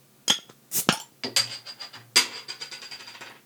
• opening beer bottle 330ml A.wav
Recorded in a small apartment studio with a Tascam DR 40
opening_beer_bottle_330ml_A_jxu.wav